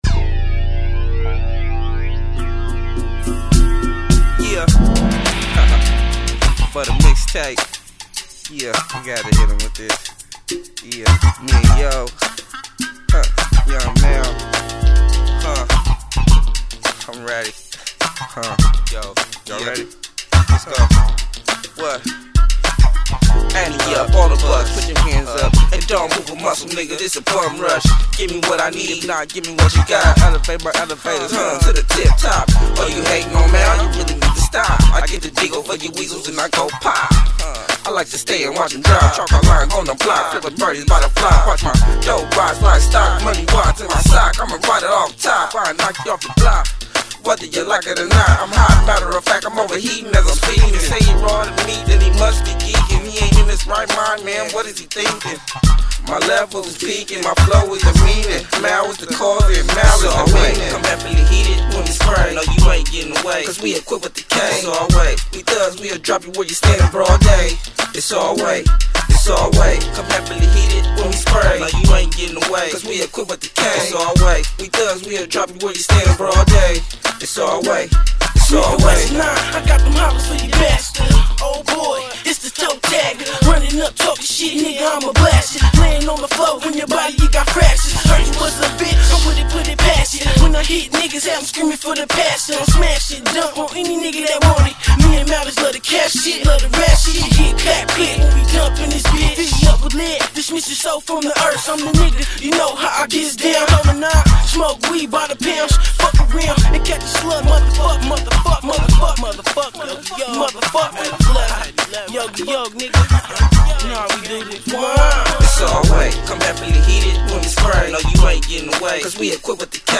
is a charismatic M.C. with flows of a young Don.